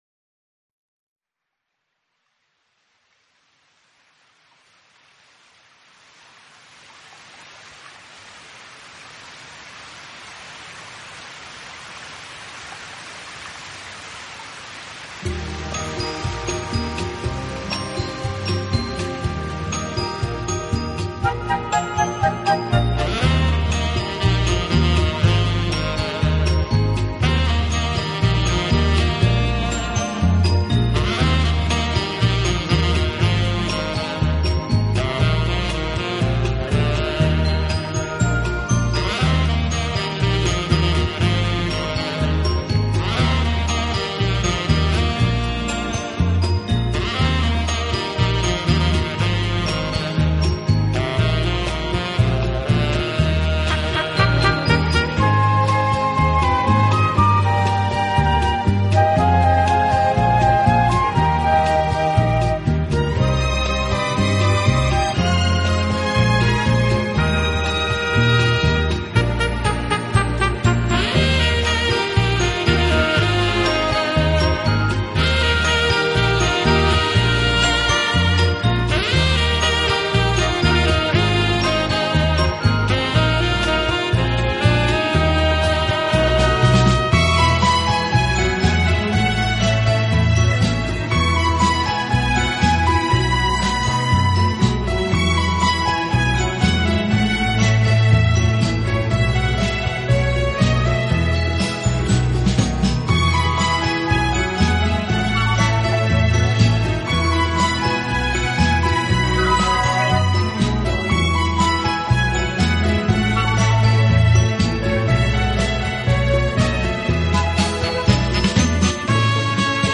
音乐风格：New Age
器配置，使每首曲子都呈现出清新的自然气息。